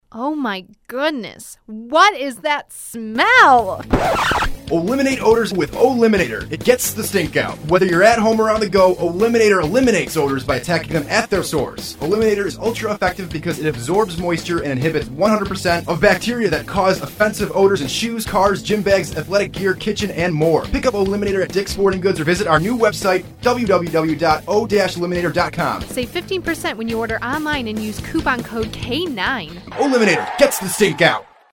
Our commercial is here! As previously blogged about, O-Liminator has a commercial running during every home Bears game on Fox Sports Radio, AM 1280.